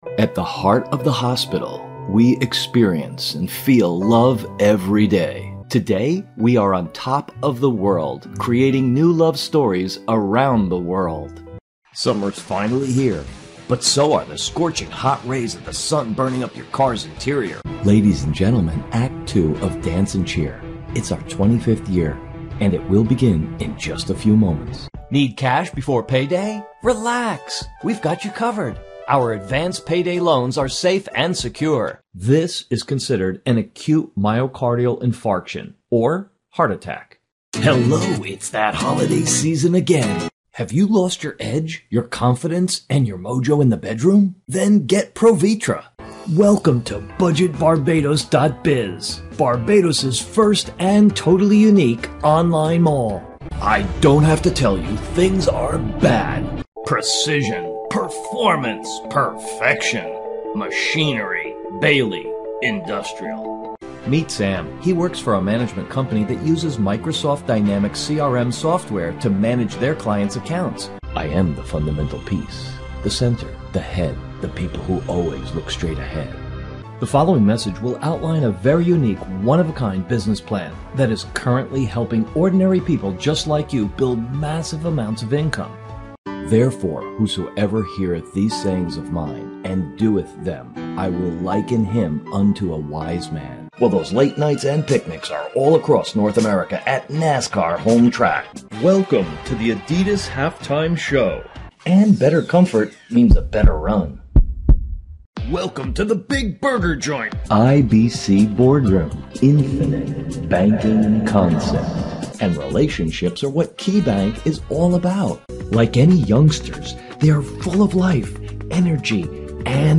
Warm calm friendly ear massaging voice for narrations, dynamic funny affirrmative active for commercials, smooth professional corporate for presentations and flexible chameleon for characters....